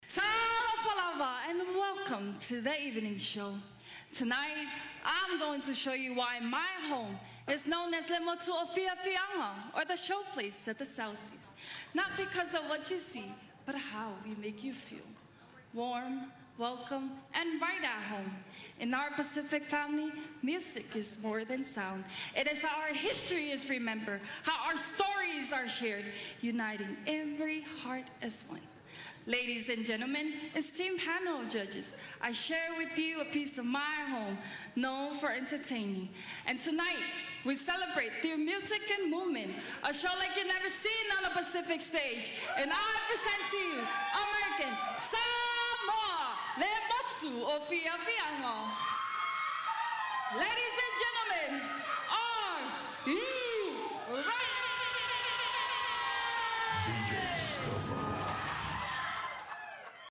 a mix of Fijian and island songs
spectators dancing, waving their flags and cheering